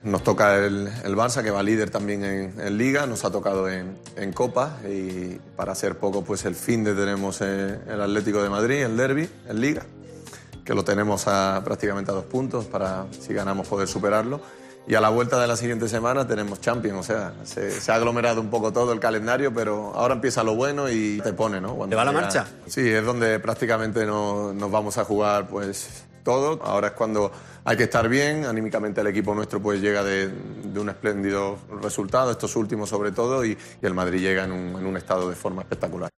El capitán del Real Madrid aseguró en El Hormiguero que "anímicamente el equipo está muy bien".